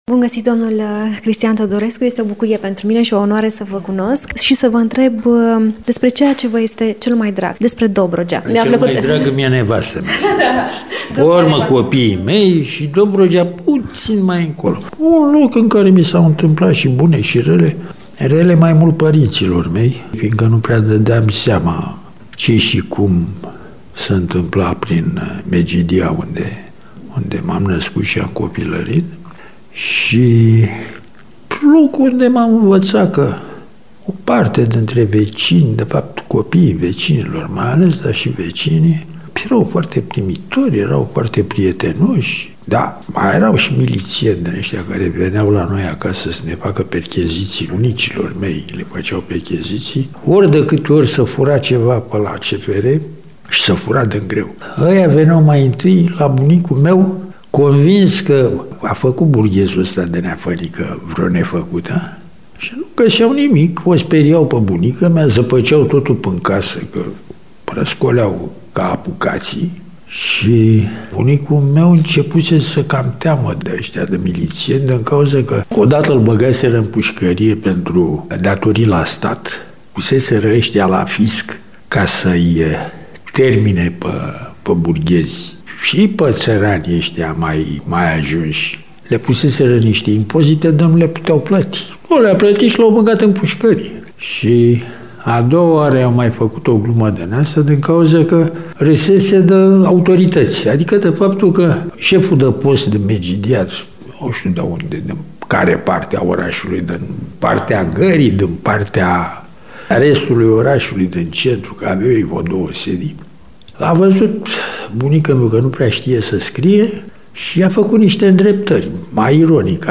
Un loc în care a simțit îmbrățișarea bunicilor, dragostea părinților, dar și neajunsurile perioadei comuniste. Despre toate acestea ne povestește, de Ziua Dobrogei, Cristian Teodorescu, într-un interviu acordat în exclusivitate pentru Radio Constanța: